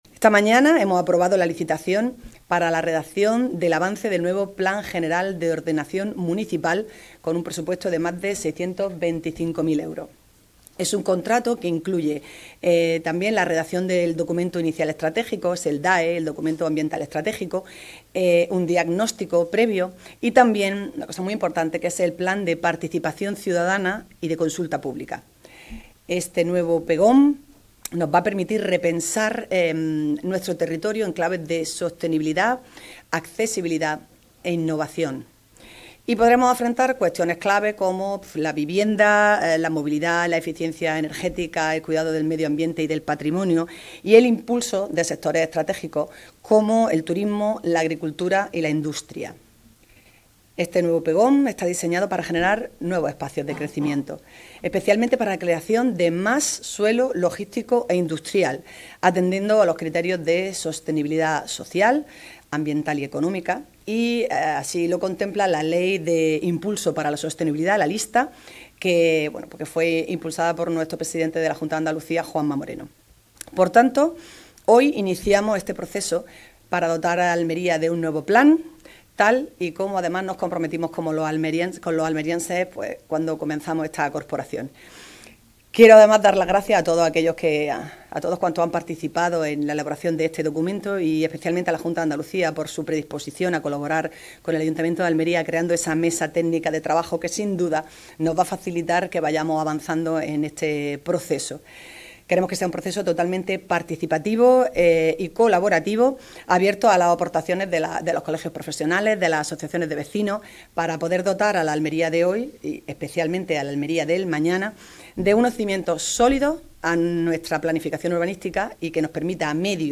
CORTE-ALCALDESA-PGOM.mp3